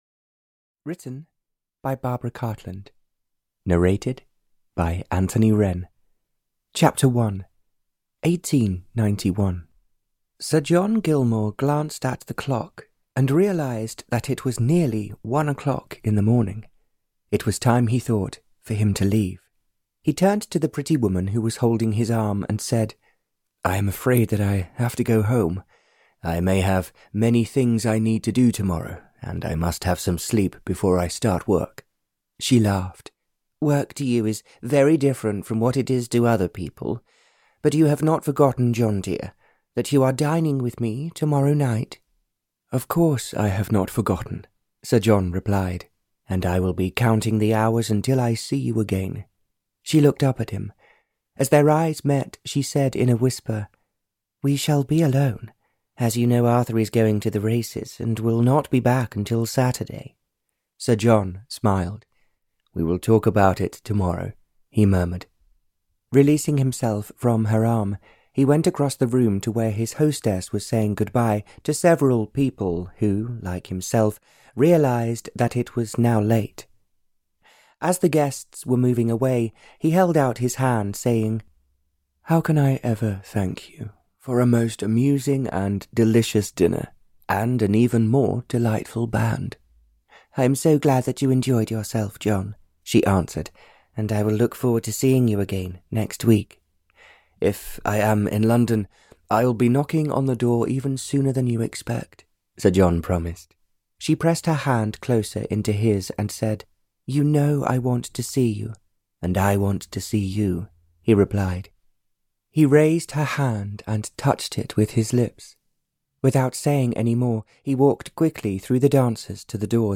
Their Search for Real Love (EN) audiokniha
Ukázka z knihy